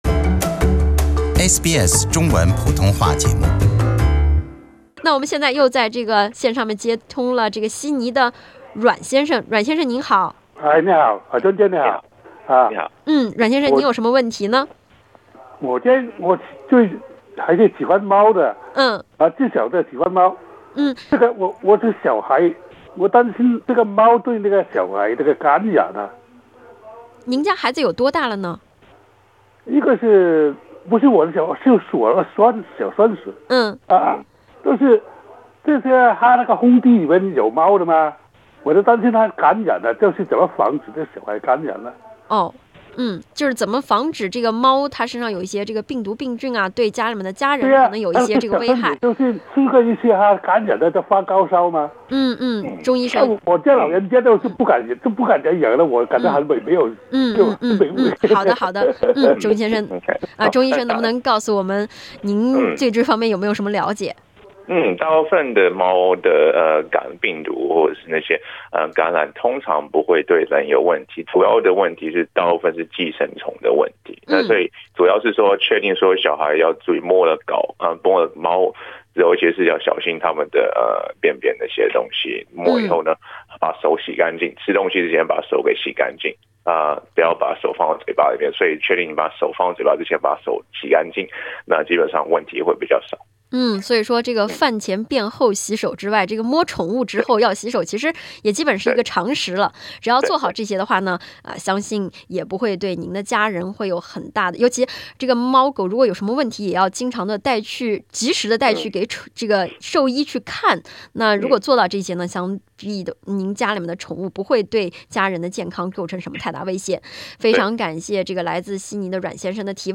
听众提问